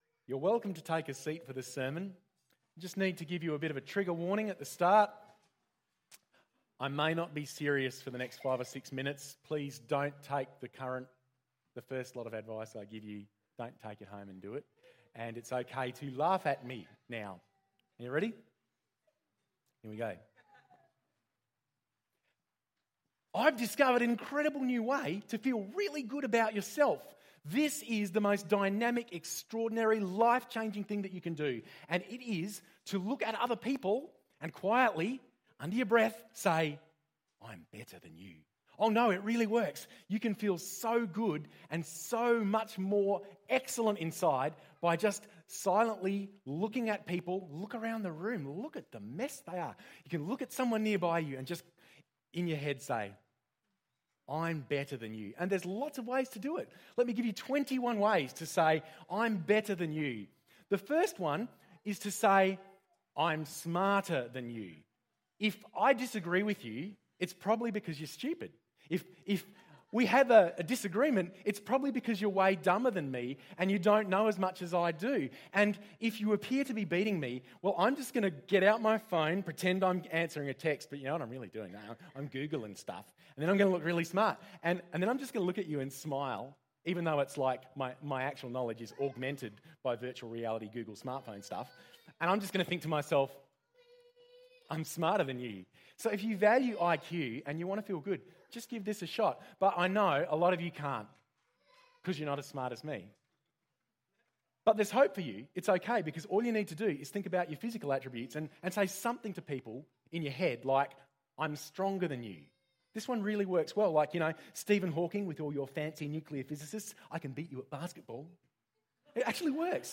It’s OK to laugh! We had a ladder up the front which the children had used to work out the ‘class system’ in the time of Jesus… and where he fit!